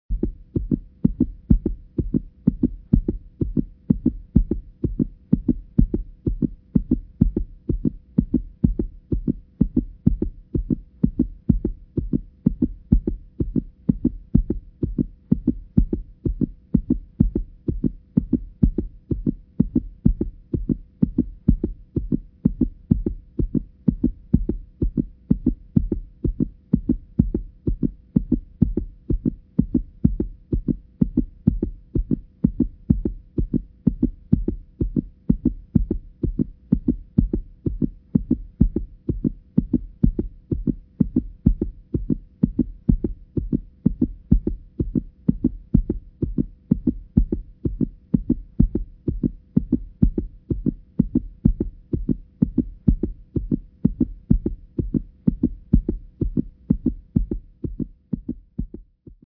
جلوه های صوتی
دانلود صدای ضربان قلب تند انسان از ساعد نیوز با لینک مستقیم و کیفیت بالا